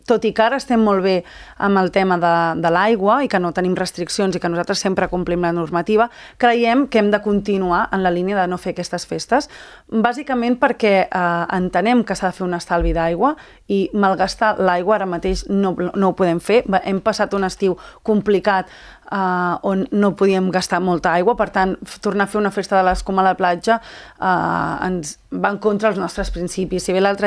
Ho ha confirmat la tinent d’Alcaldia de Promoció econòmica i Turisme, Cindy Rando, a l’entrevista política de Ràdio Calella TV.